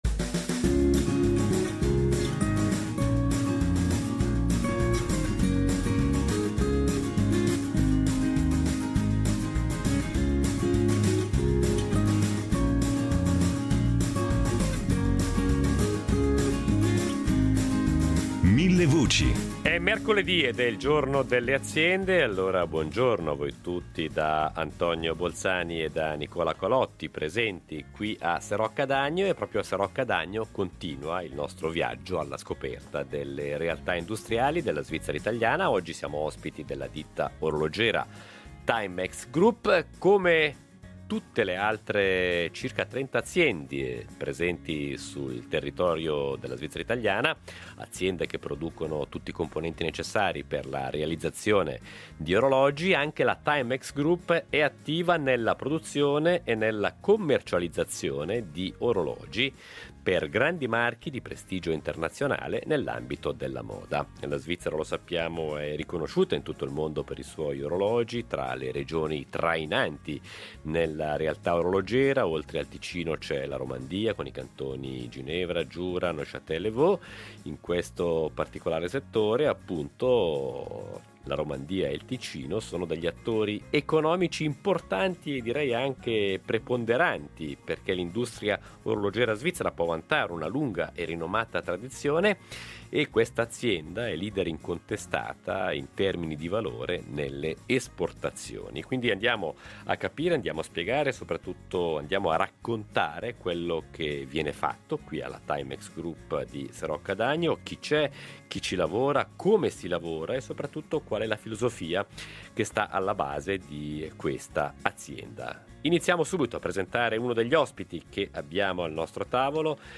In diretta dalla Timex Group di Serocca d’Agno